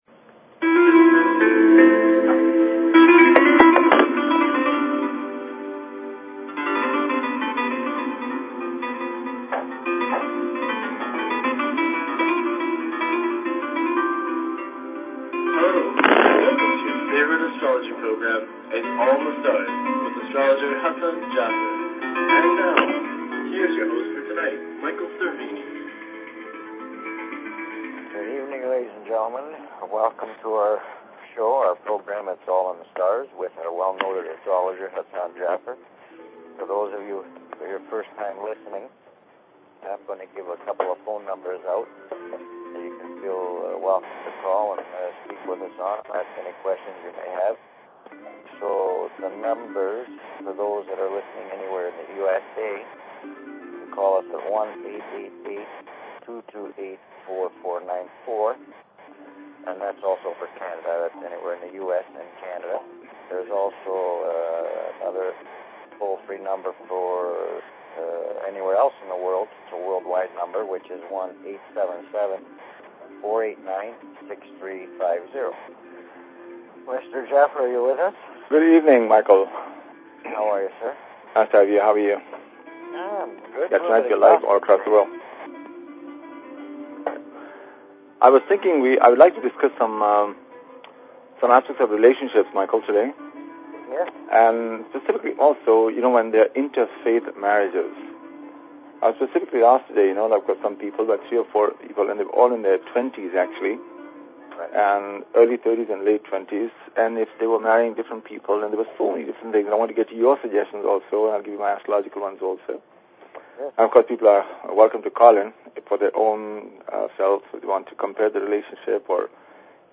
Talk Show Episode, Audio Podcast, Its_all_in_the_Stars and Courtesy of BBS Radio on , show guests , about , categorized as